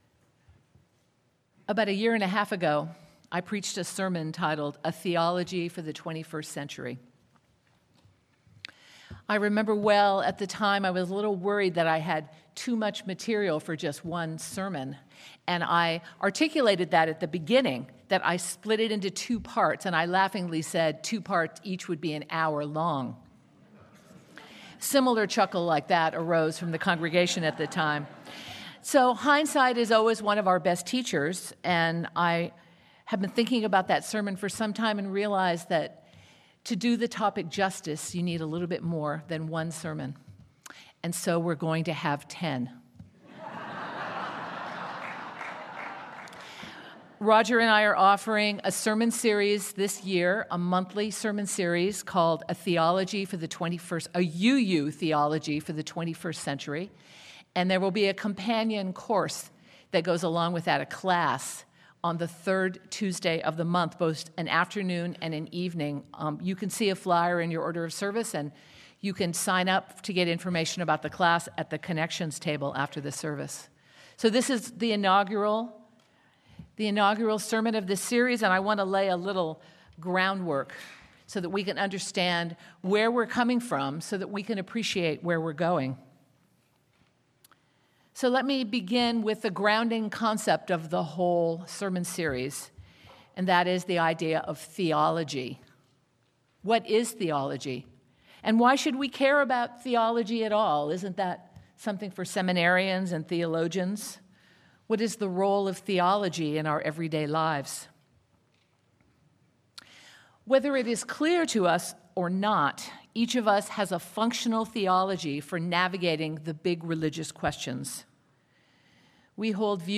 In this kick-off sermon for the series, we will consider the origins of our current UU theology and what changes are relevant for our times.
Sermon-UUTheology21C-1.mp3